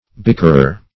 Bickerer \Bick"er*er\, n.